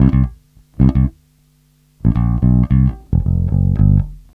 Funk Master Bass.wav